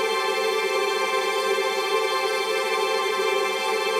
GS_TremString-C6+9.wav